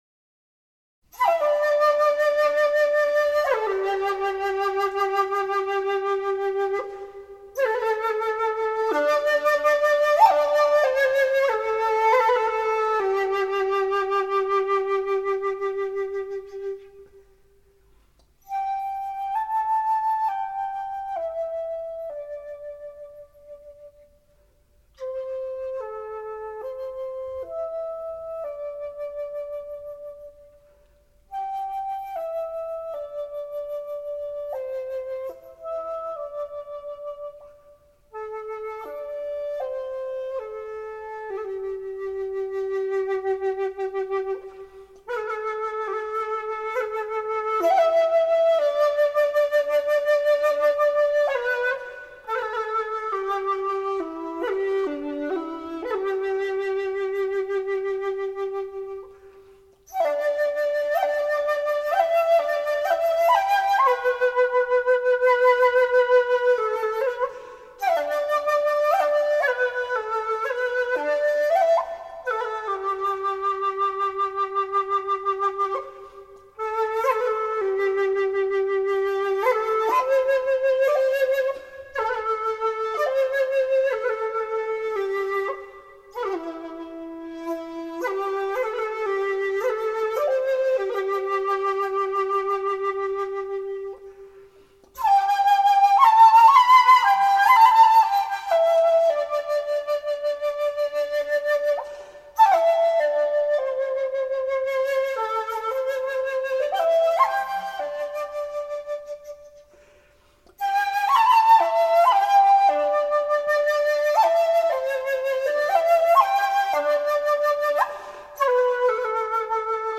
国乐名曲 清爽恬淡 颇有雅趣
洞箫的充沛空气感、琵琶、杨琴的特殊弦乐之美，通通收录其中。
如饭后清茶一般的清爽恬淡，颇有雅趣。